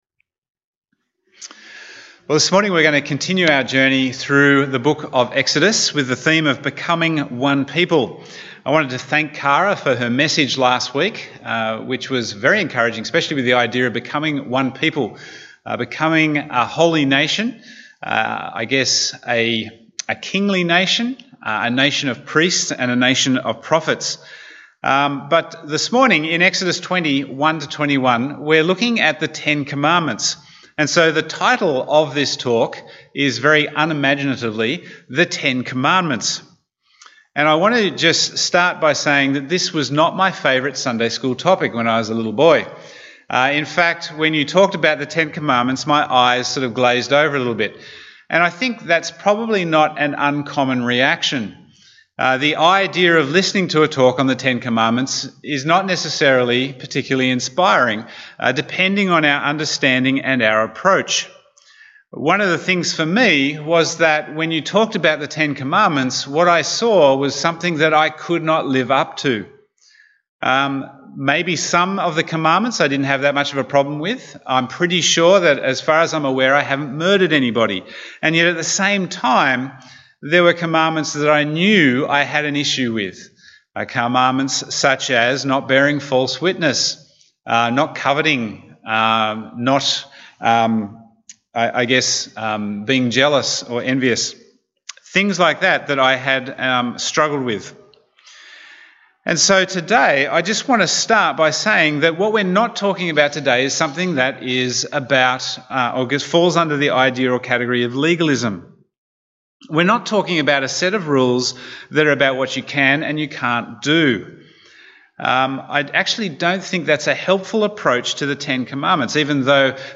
Bible Text: Exodus 20:1-21 | Preacher